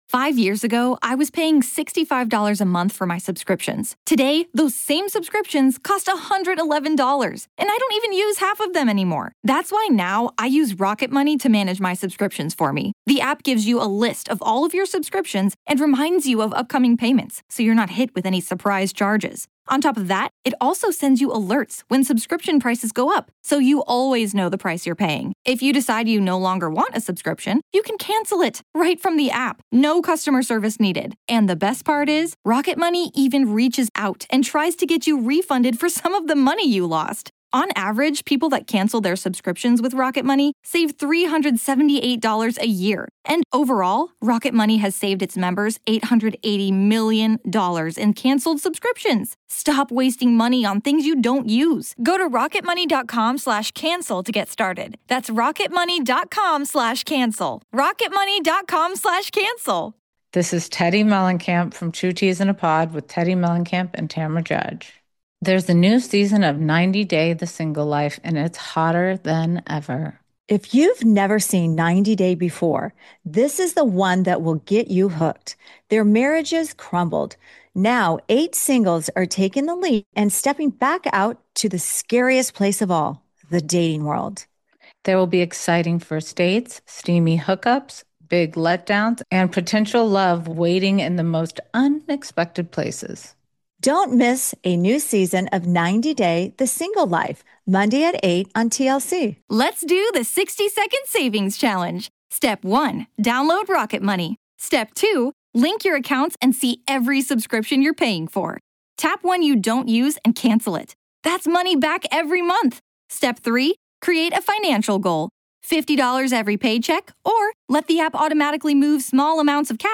Join us for Part One of this riveting conversation